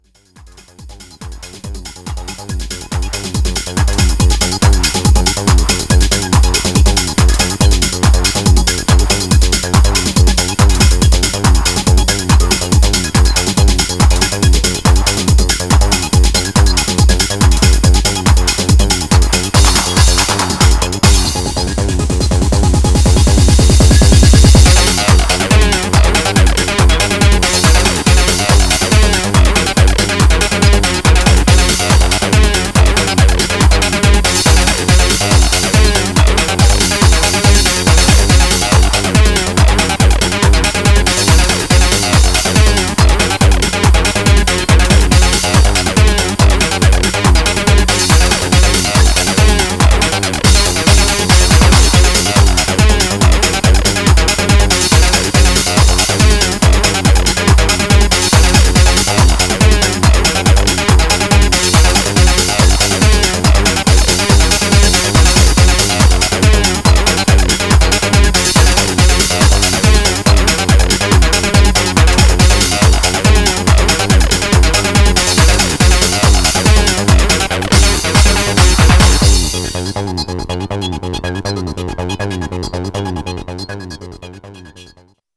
Styl: Techno, Hardtek/Hardcore